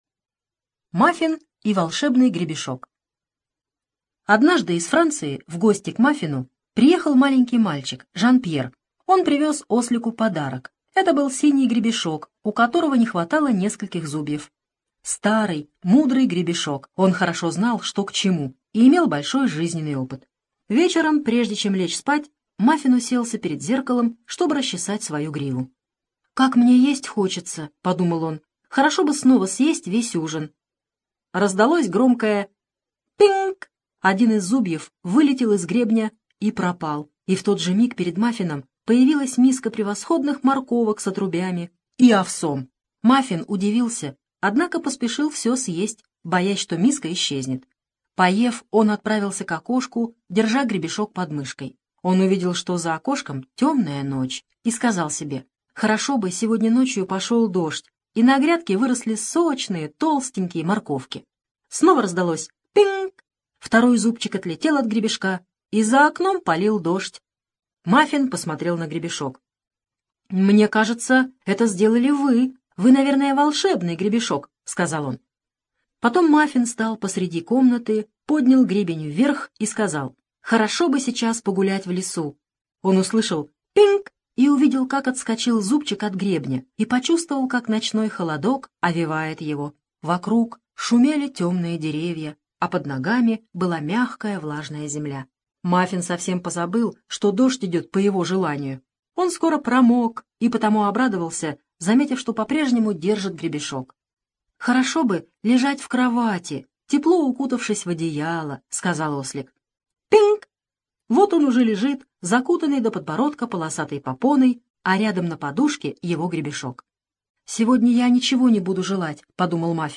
Аудиосказка «Мафин и волшебный гребешок»